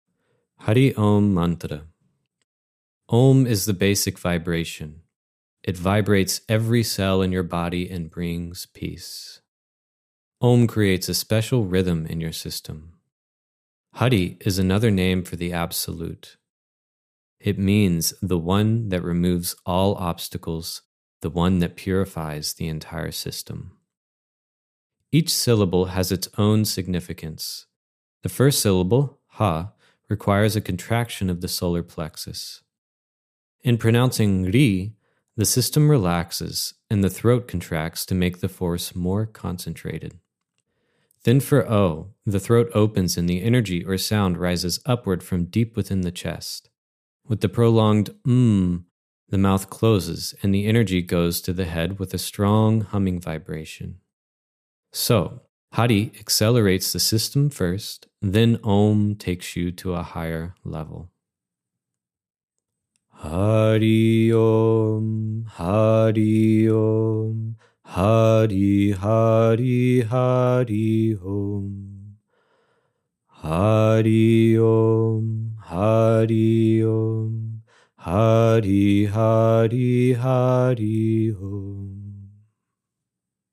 Hari OM Chanting Info
Hari-OM-Chanting-Info.mp3